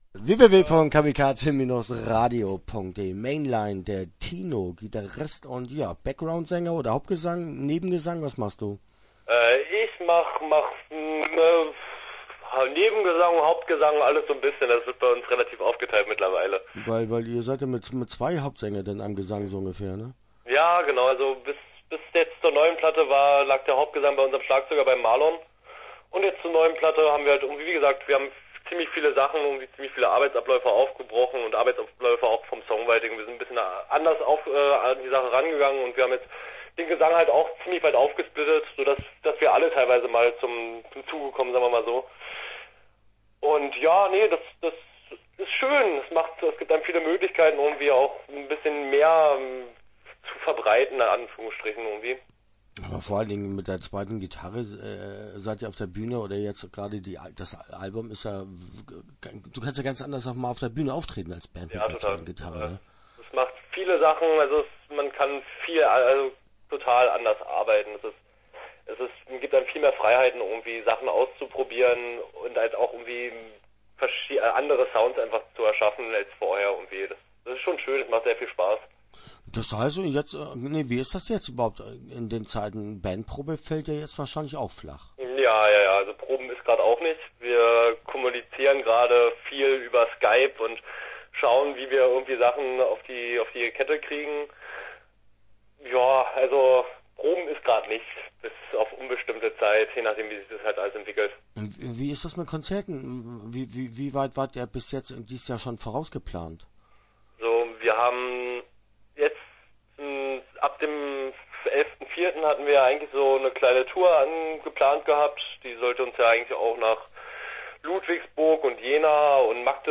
Start » Interviews » Mainlined